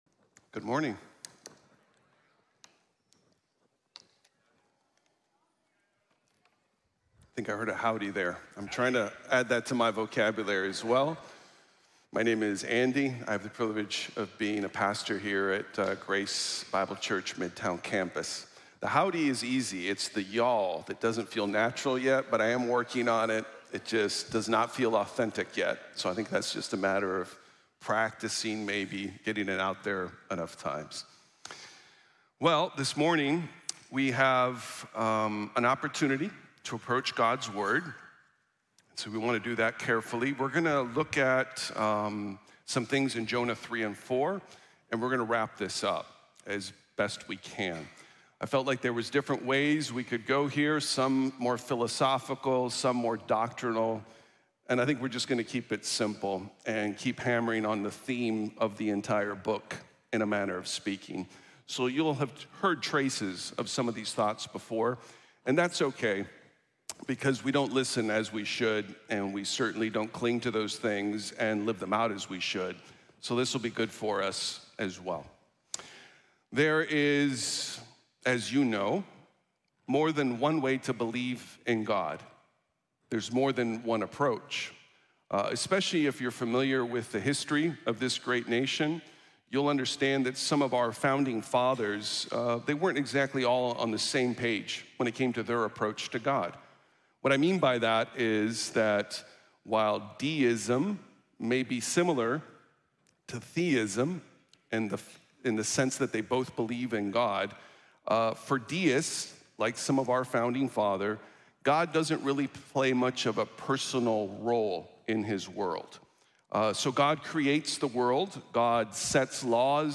Él se preocupa | Sermón | Iglesia Bíblica de la Gracia